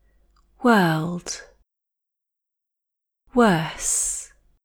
Make sure that the /w/ sounds like a high-energy /u/ sound and then for the vowel, totally relax the mouth in received pronunciation (/ɜː/ sounds like a drawn-out schwa).